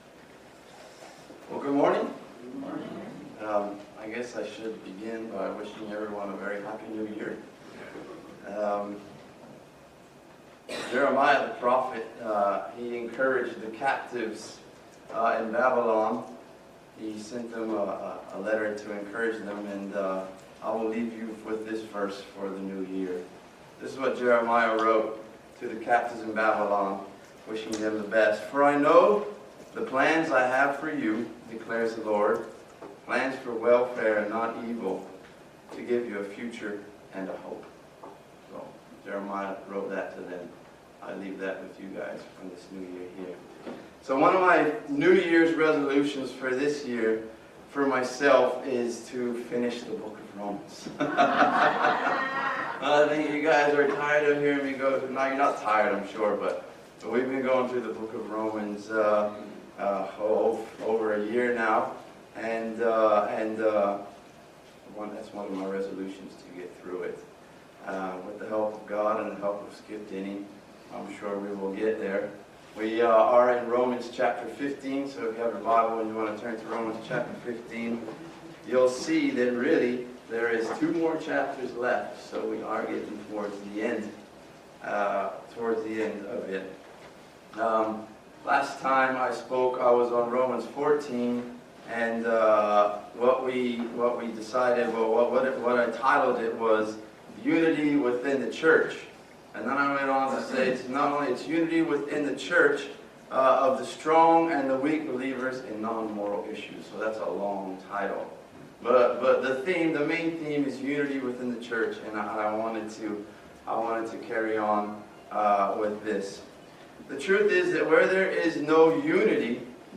Romans 15:1-6 Service Type: Family Bible Hour God expects that we consider others